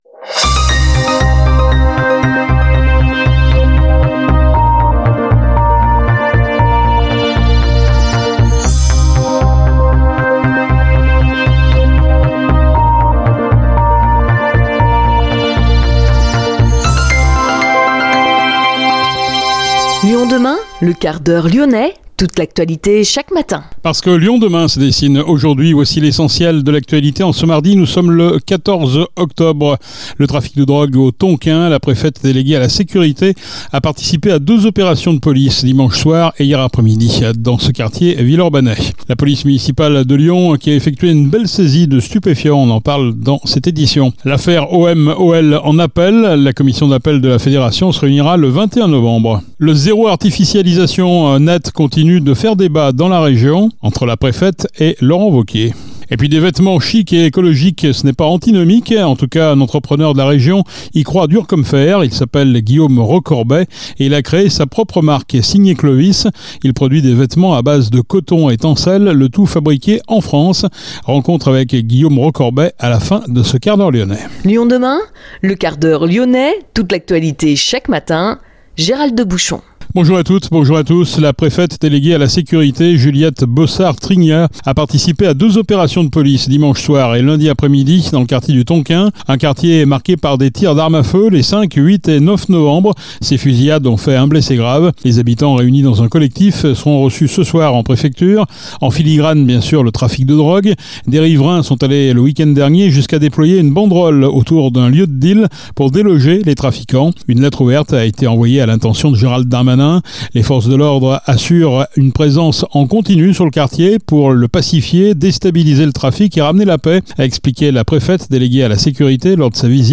LE 1/4H LYONNAIS | mardi 14 novembre 2023